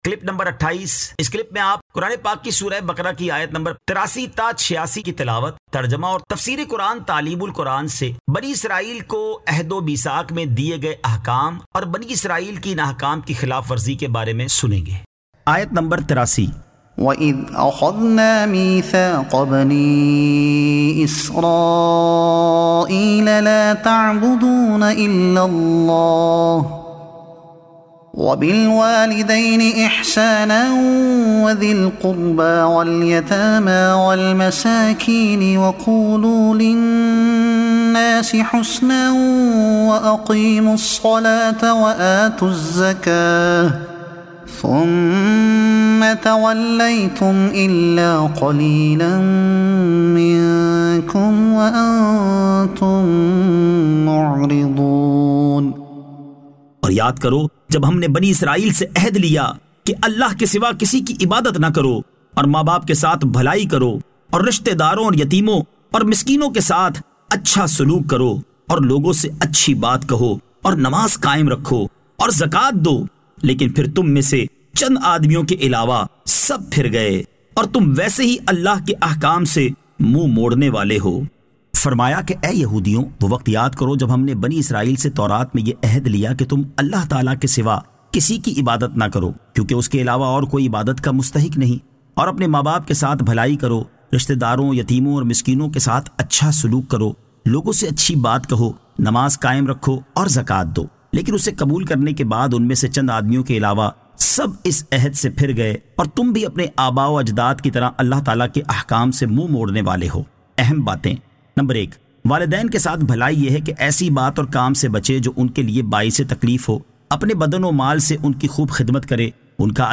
Surah Al-Baqara Ayat 83 To 86 Tilawat , Tarjuma , Tafseer e Taleem ul Quran